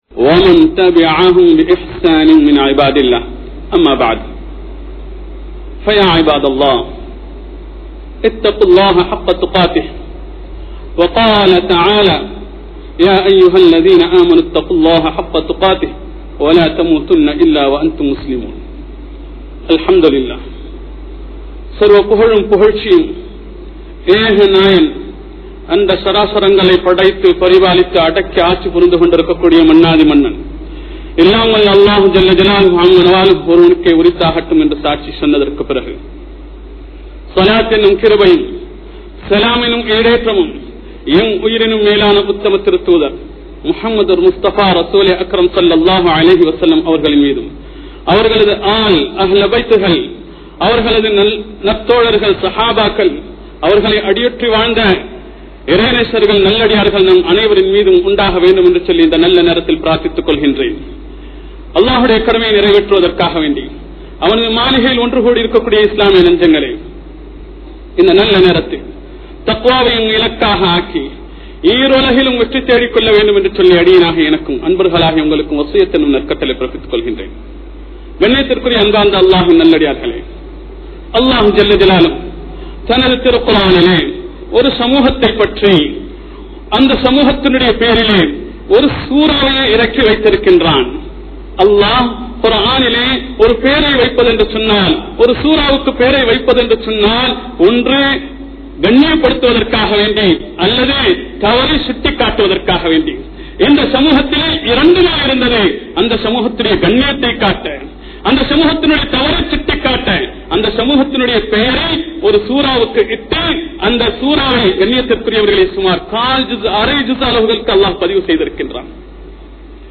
Islam Koorum Suththam (இஸ்லாம் கூறும் சுத்தம்) | Audio Bayans | All Ceylon Muslim Youth Community | Addalaichenai
Colombo 03, Kollupitty Jumua Masjith